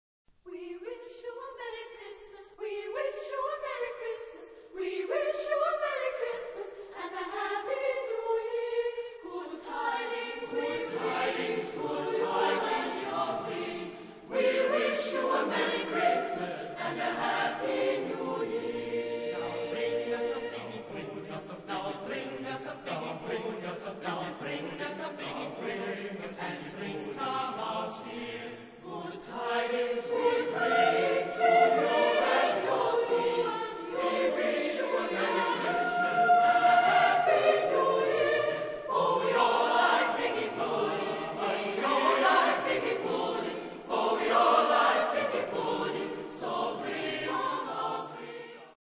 Chorgattung: SATB  (4 gemischter Chor Stimmen )
Tonart(en): B-dur